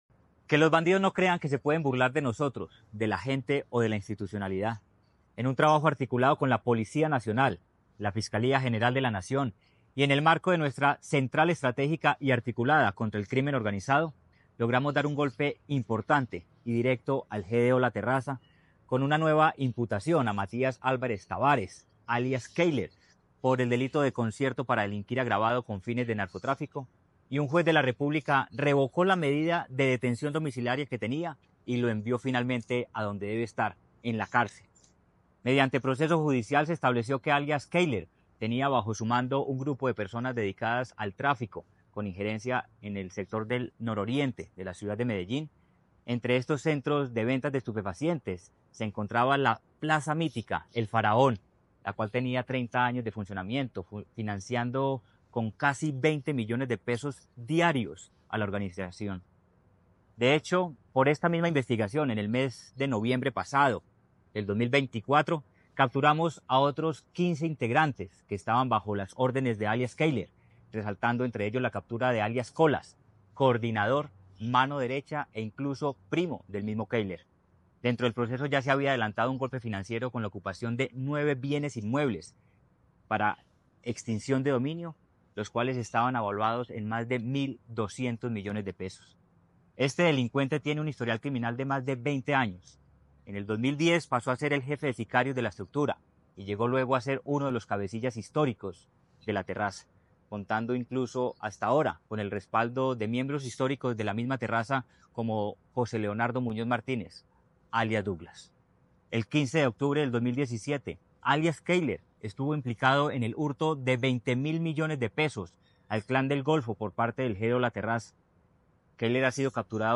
Audio Palabras de Manuel Villa Mejía, secretario de Seguridad y Convivencia En un nuevo golpe contra el crimen organizado